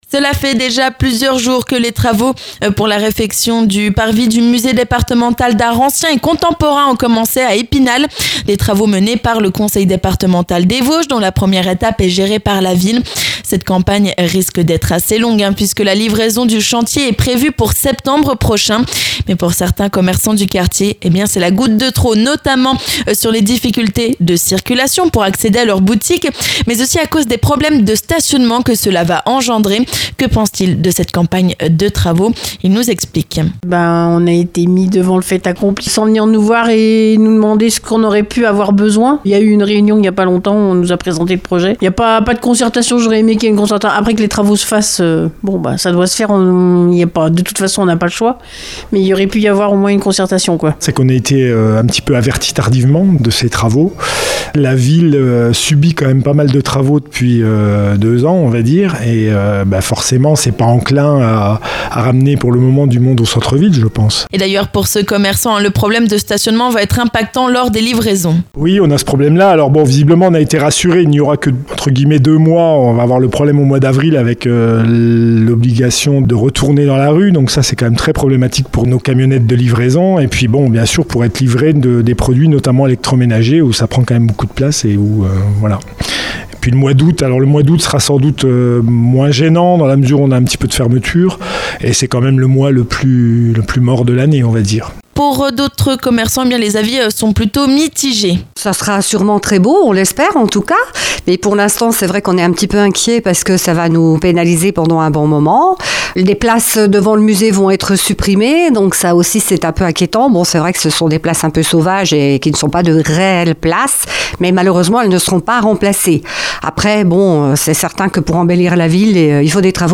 Notamment à cause des problèmes de circulation et stationnement qui pourrait avoir un impact sur la fréquentation de leurs boutiques. Nous sommes allés leur tendre notre micro.